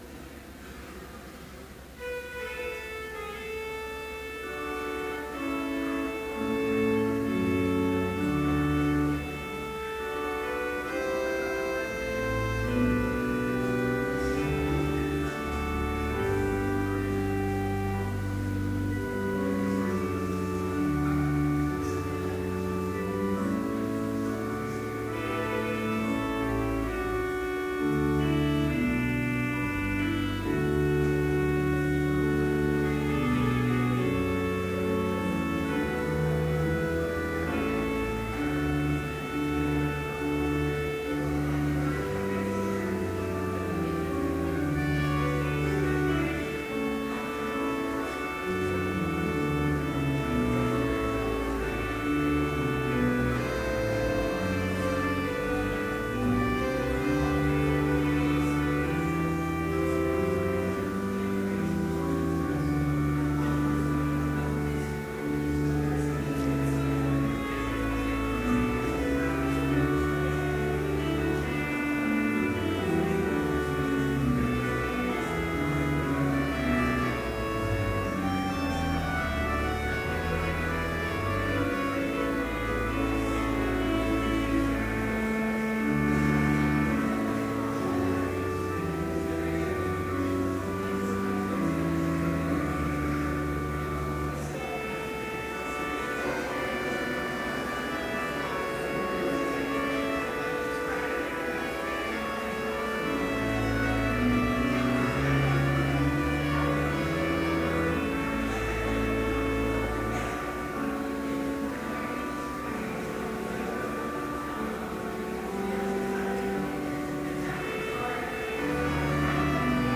Complete service audio for Chapel - January 28, 2013
Order of Service Prelude Hymn 411, vv. 1-3, Christians, Come in Sweetest Measures Reading: Luke 14:16-24 Homily Prayer Hymn 413, vv. 1, 2 & 4, Come unto Me, Ye Weary Benediction Postlude